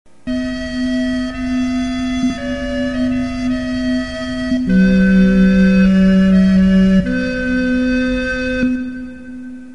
Recorder
For recording purposes he used soprano, alto and tenor and took the strongest notes on each instrument. There may also be one or two bass recorder notes at the bottom.
At present, only the vibrato-less version is available.
recorder.mp3